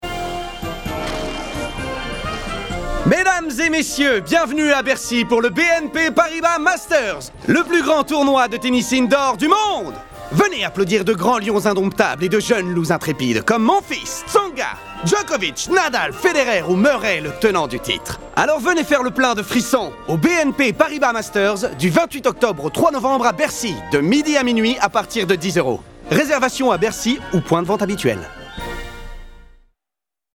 Pub BNP Paribas